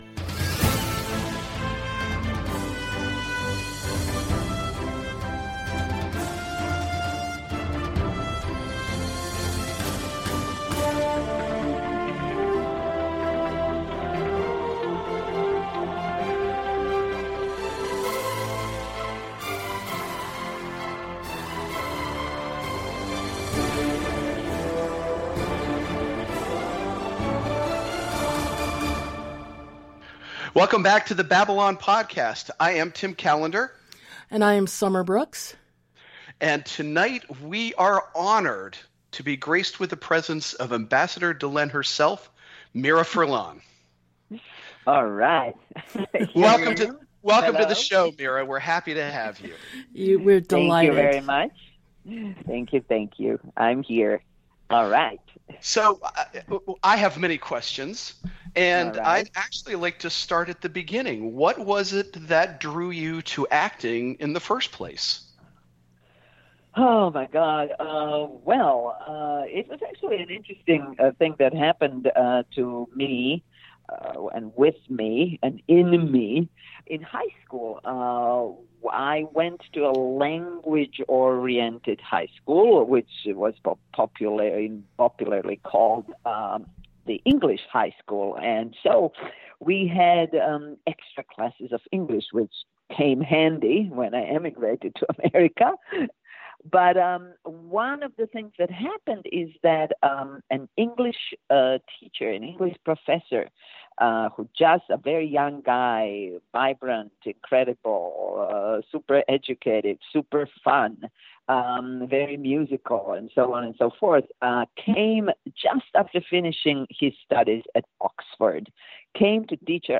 BabylonPodCastMiraInterview.mp3